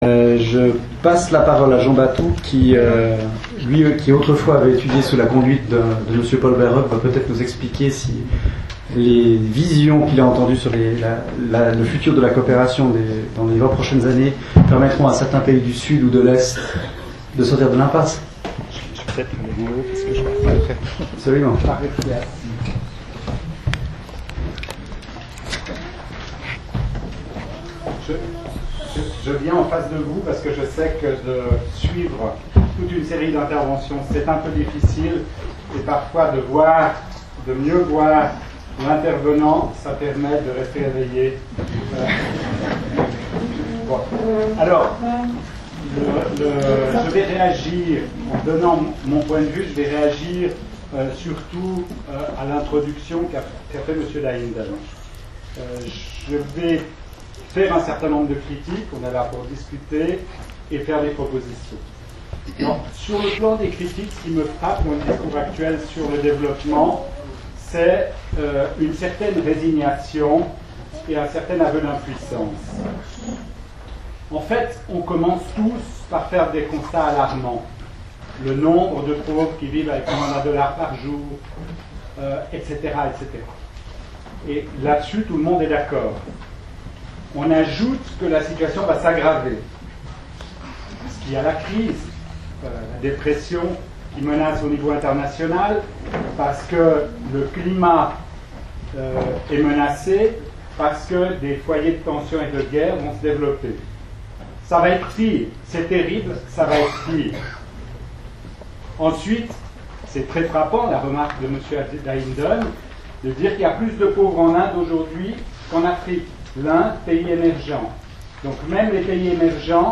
Intervention de Jean Batou